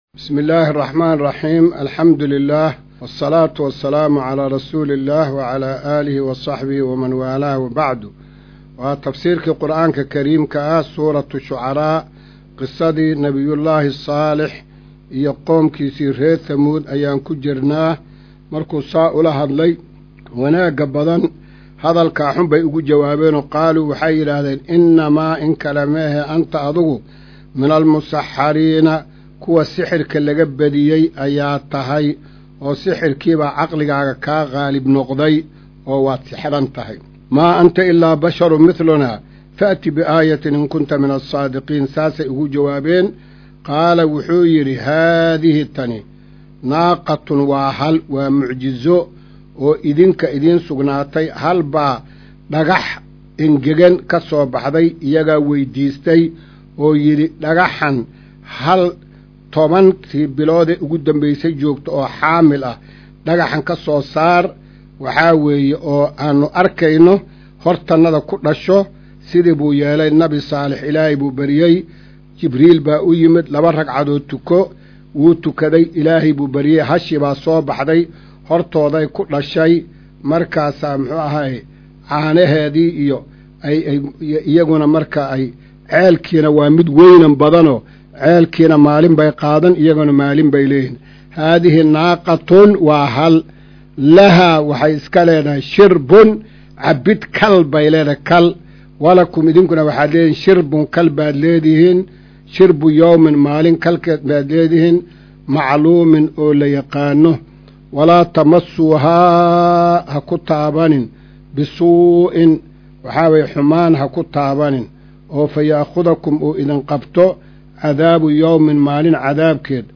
Maqal:- Casharka Tafsiirka Qur’aanka Idaacadda Himilo “Darsiga 180aad”